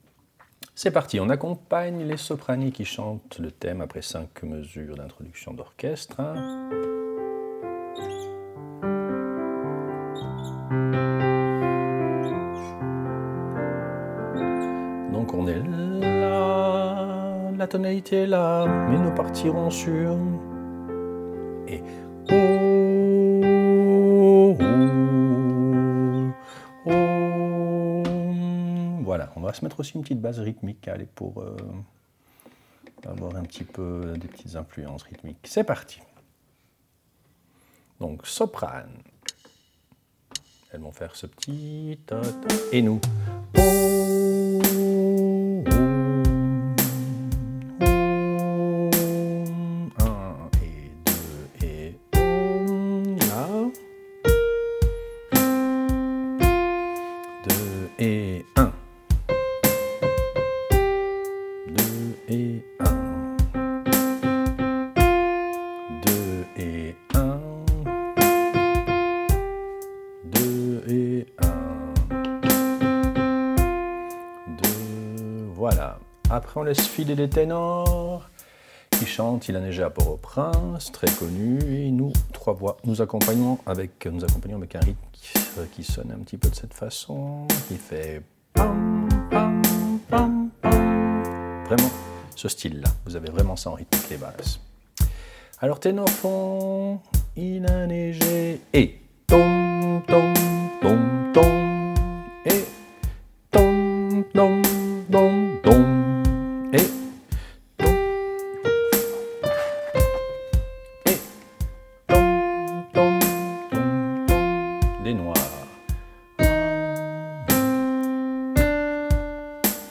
Répétition SATB4 par voix
Basse